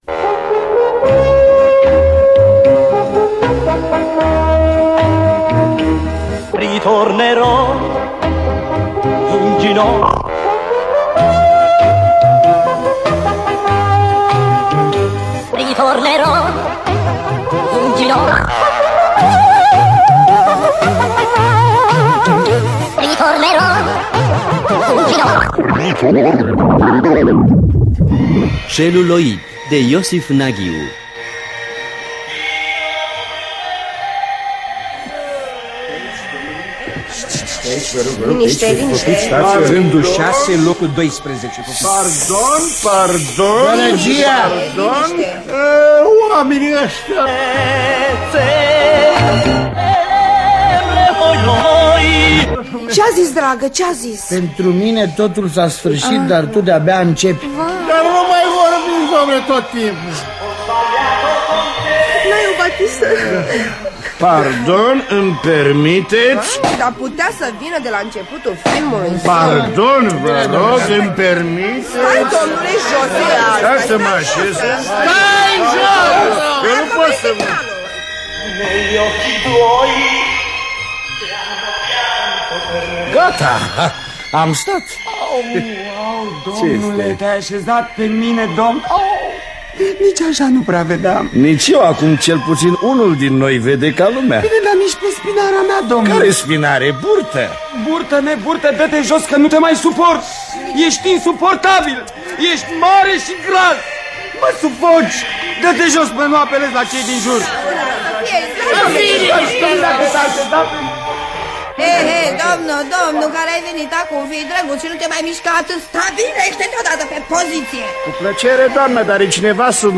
Celuloid de Iosif Naghiu – Teatru Radiofonic Online